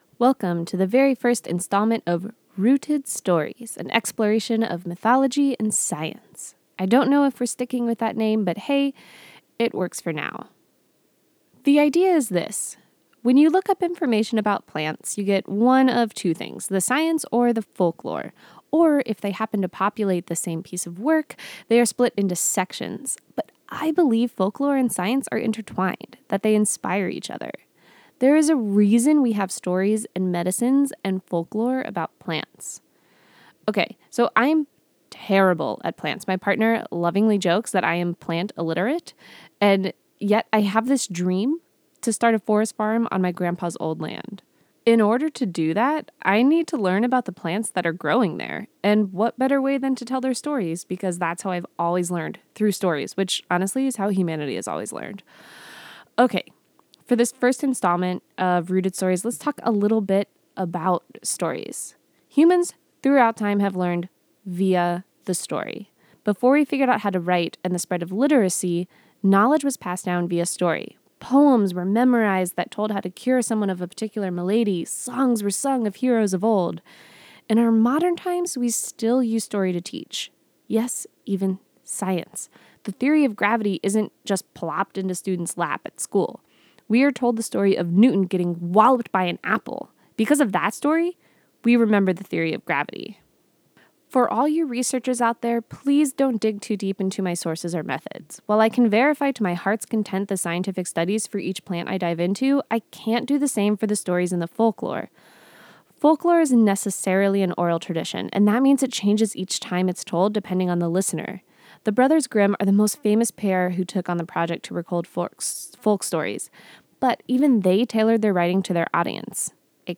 I recorded it like a podcast.